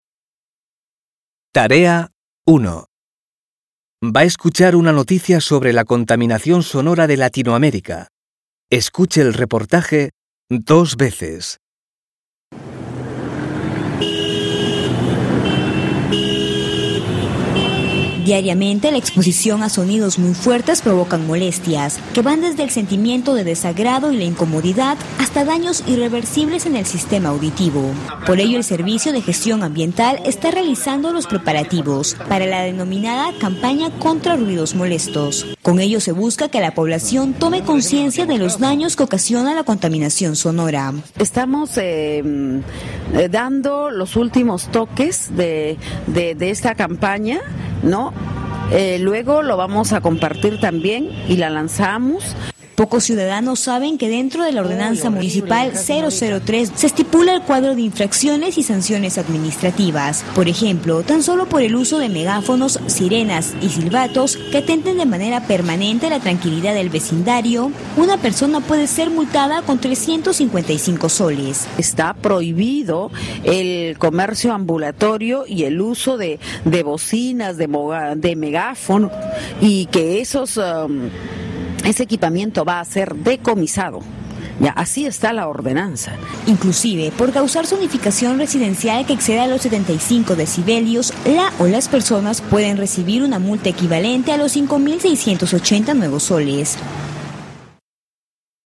Tras escuchar una noticia sobre la contaminación sonora de una ciudad de Latinoamérica, usted ha decidido escribir un correo electrónico al alcalde de su ciudad para proponer algunas soluciones al problema. Escuche la noticia dos veces.